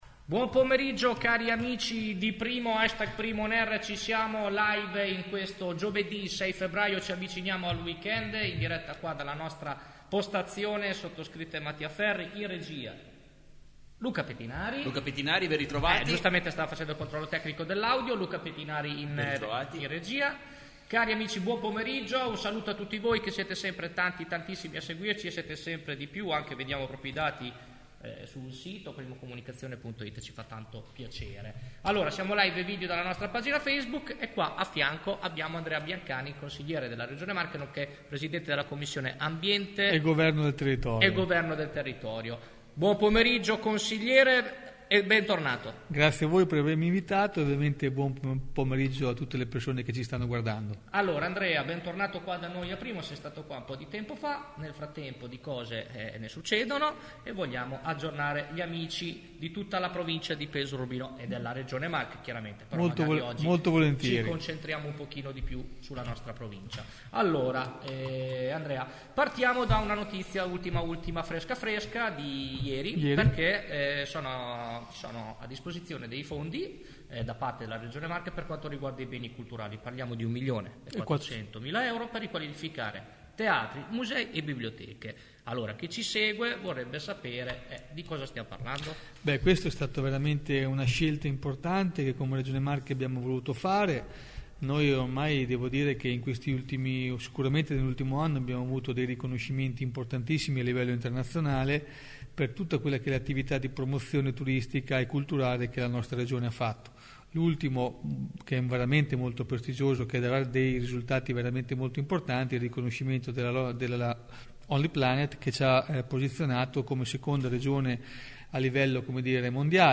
Dalla regione al territorio: intervista ad Andrea Biancani